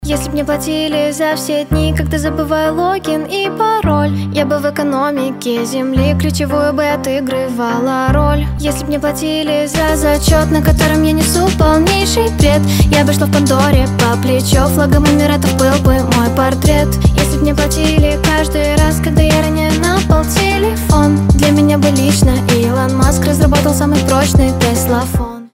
поп
забавные
красивый женский голос
пародии